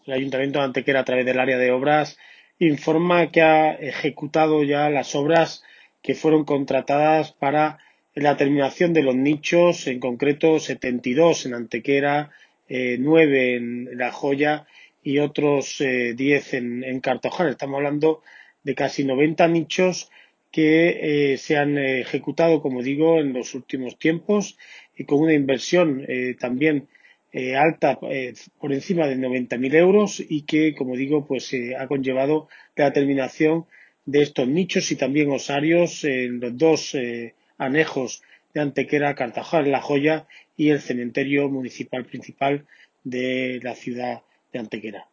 El concejal delegado de Obras del Ayuntamiento de Antequera, José Ramón Carmona, informa del desarrollo de nuevas mejores en los cementerios municipales tanto de Antequera como de las pedanías de Cartaojal y La Joya.
Cortes de voz